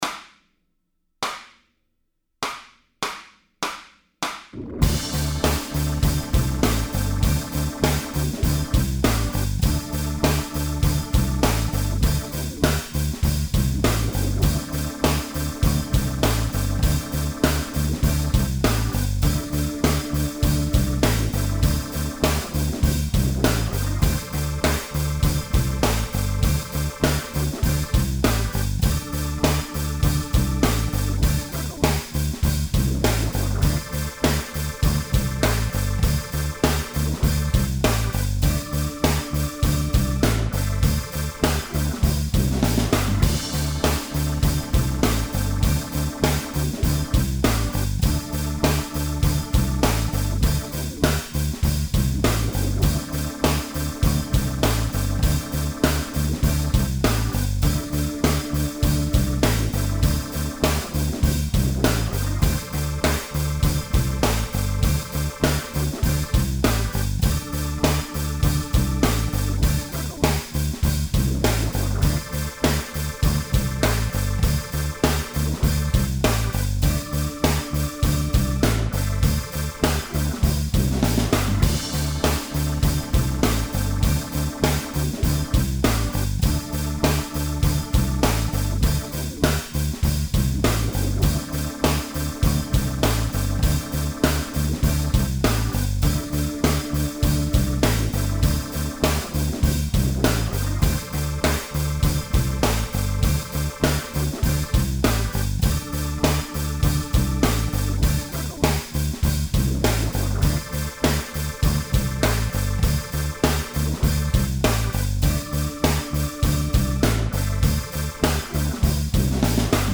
Guitar Lessons: Bending & Vibrato in Rock